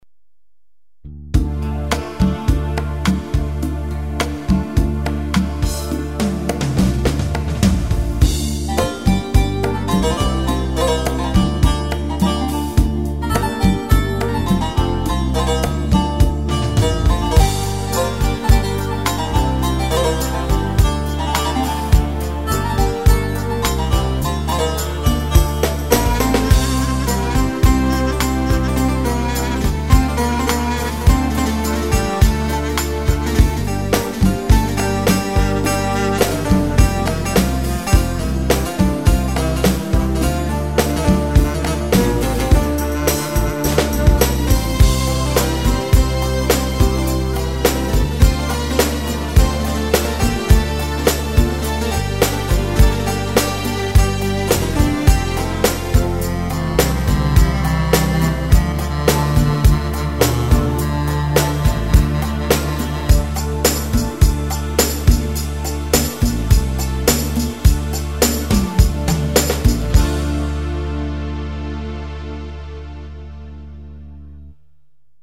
SX3000 3 demo yeni ritimlerle